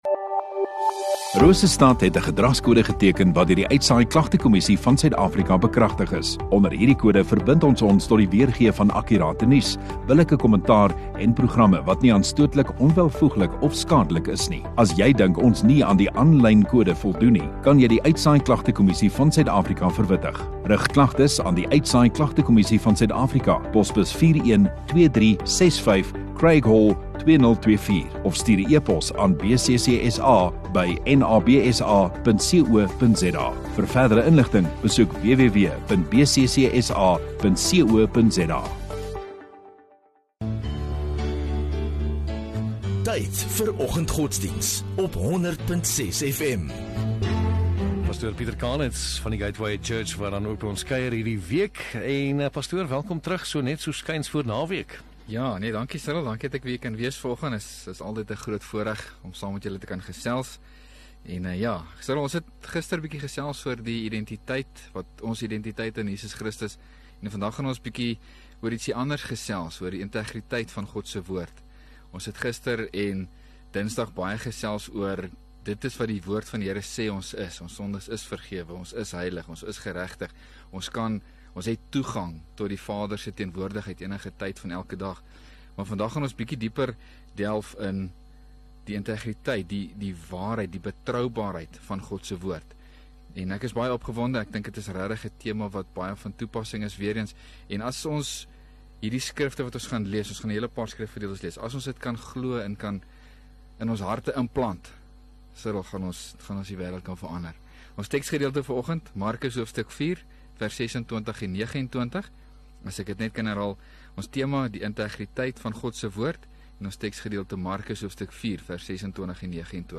7 Mar Donderdag Oggenddiens